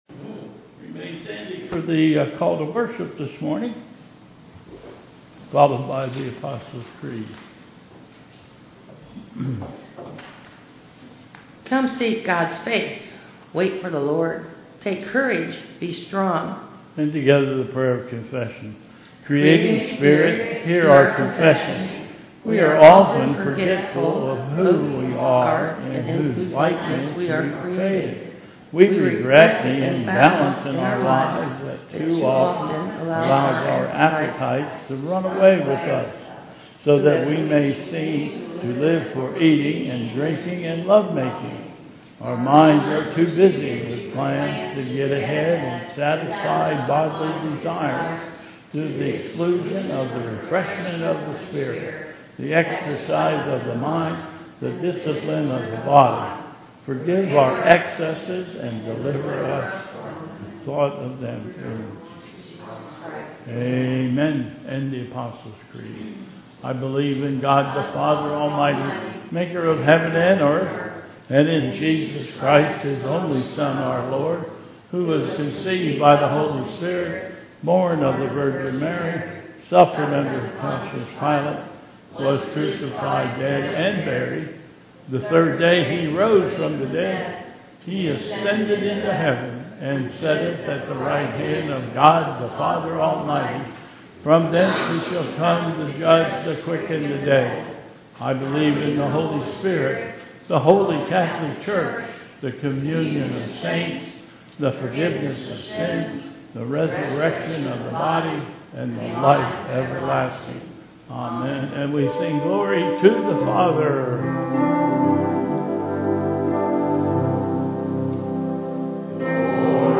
Bethel Church Service
Call to Worship